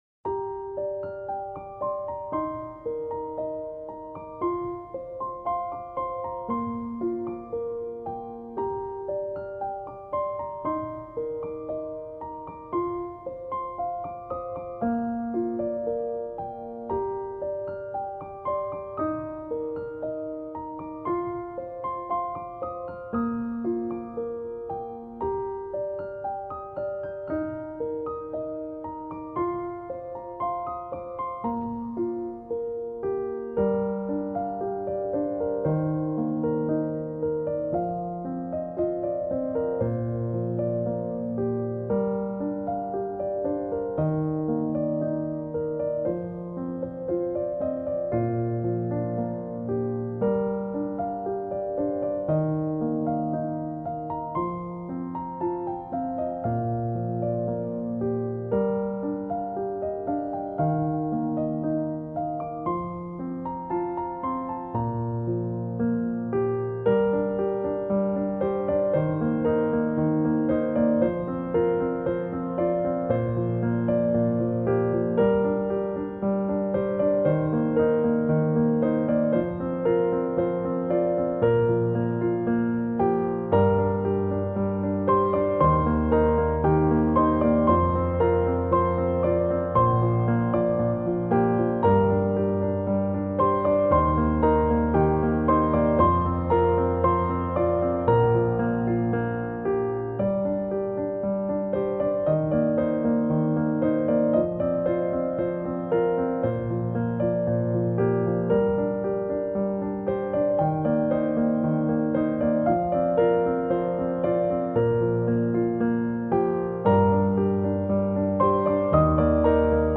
سبک آرامش بخش , پیانو , عاشقانه , موسیقی بی کلام